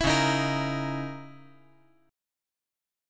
C#m6add9 Chord
Listen to C#m6add9 strummed